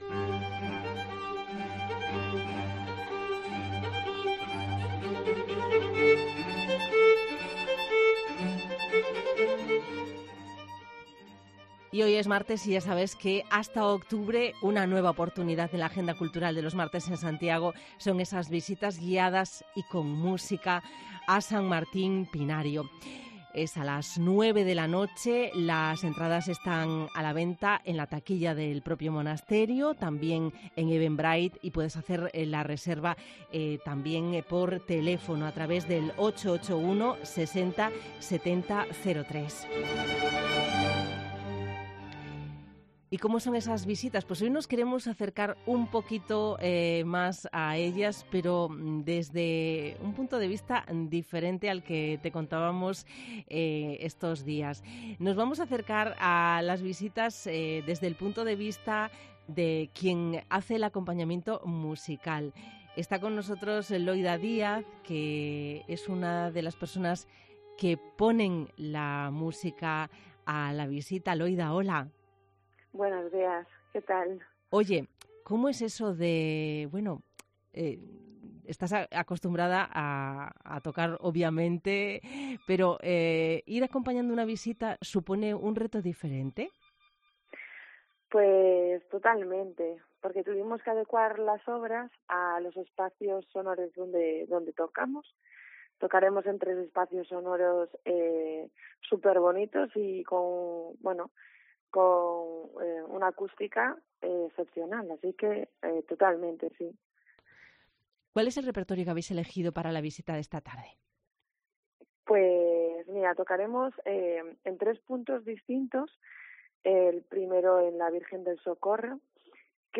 visita radiofónica